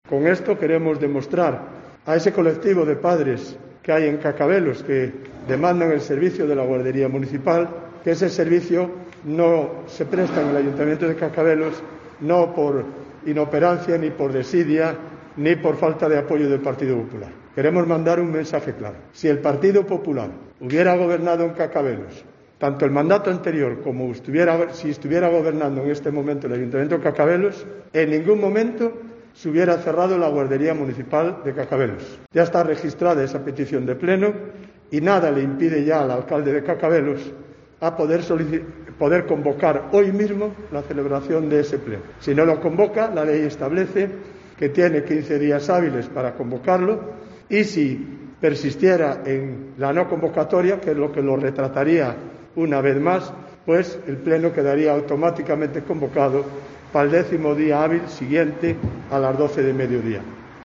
Escucha aquí las palabra de Adolfo Canedo, portavoz de los populares en la villa del Cúa